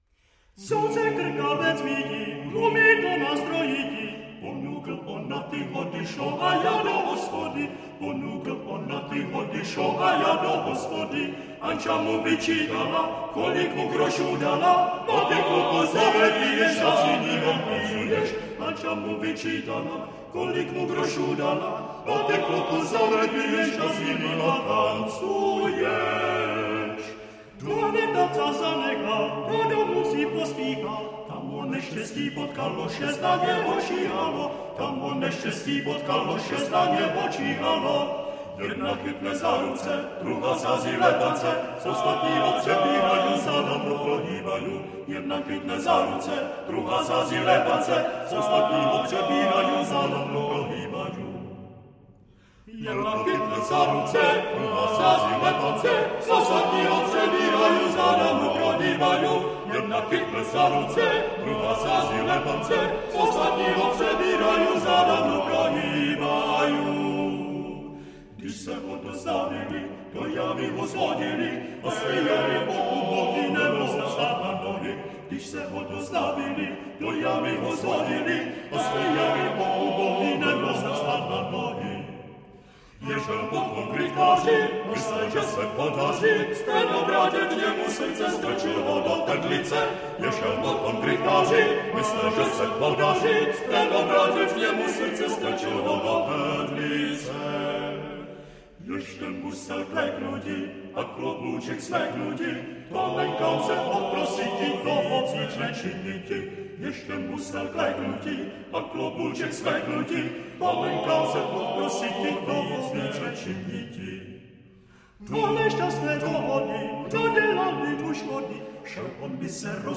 FF:VH_15b Collegium male choir